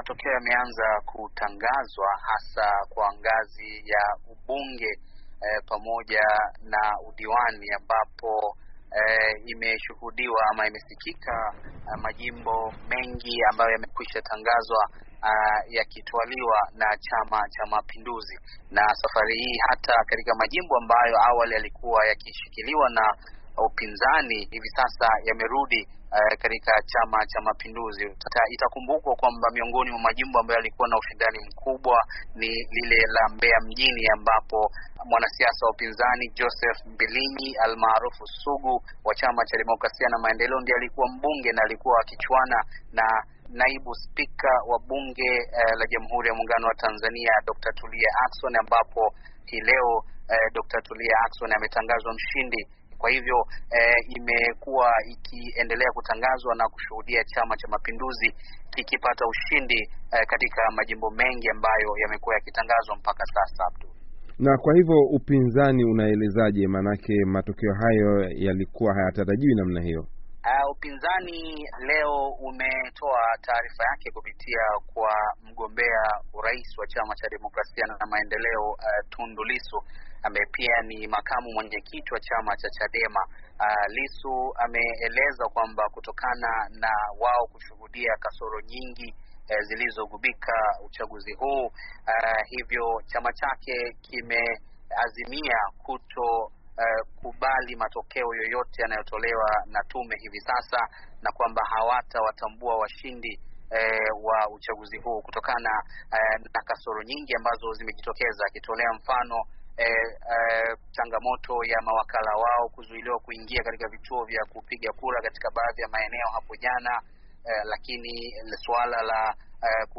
Mahojiano